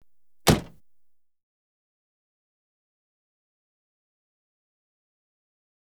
Door Car Jetta Close Sound Effect
door-car-jetta-close.wav